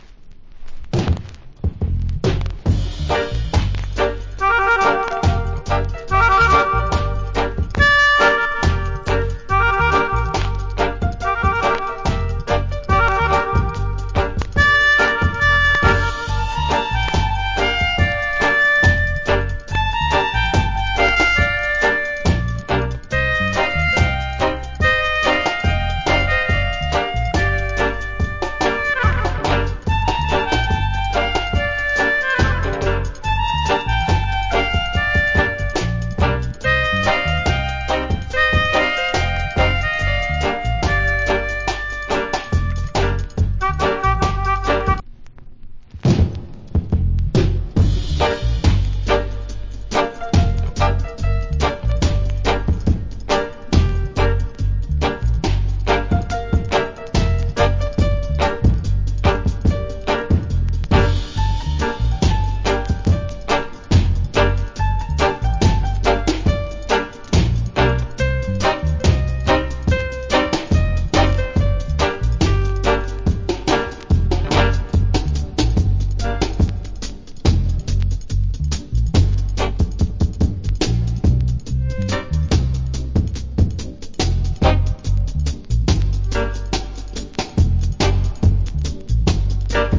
Wicked Melodica Reggae Inst.